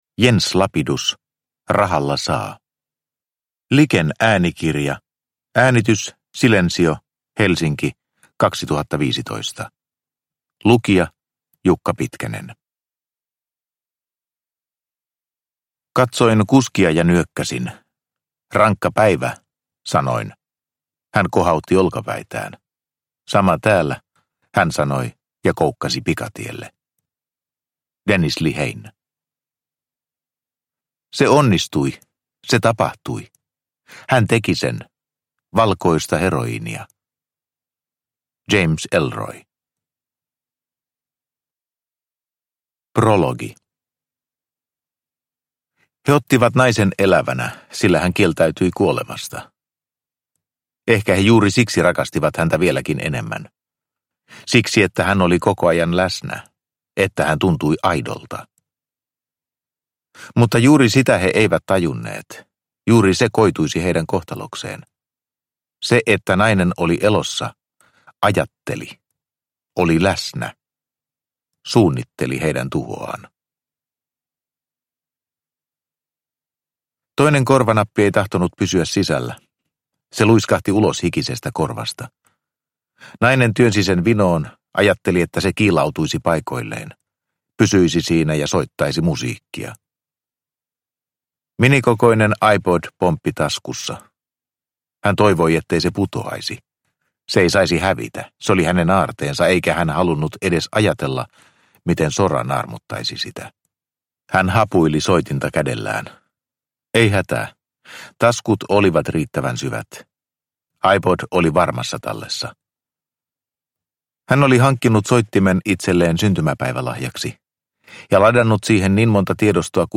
Rahalla saa – Ljudbok – Laddas ner